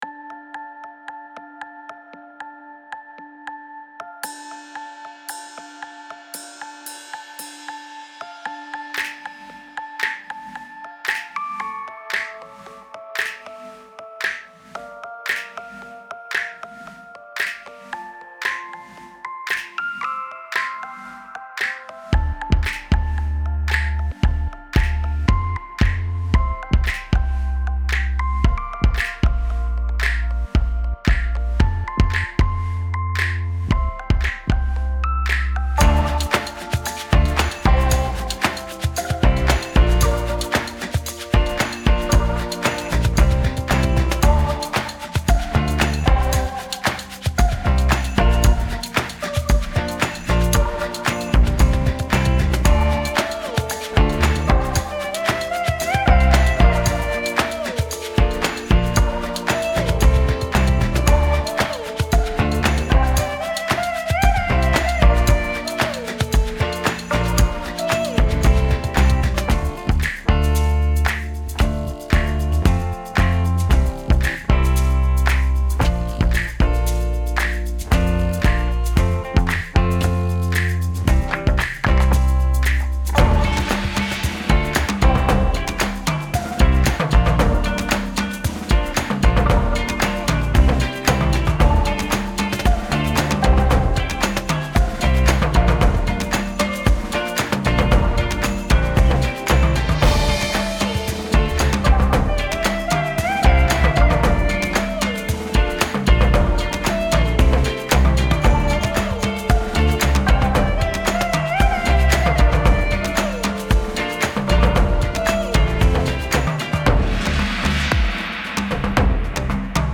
Version instrumentale spéciale spectacle (avec clic départ)